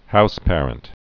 (houspârənt, -păr-)